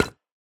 Minecraft Version Minecraft Version snapshot Latest Release | Latest Snapshot snapshot / assets / minecraft / sounds / block / decorated_pot / step3.ogg Compare With Compare With Latest Release | Latest Snapshot